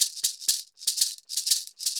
Cabasa_ ST 120_2.wav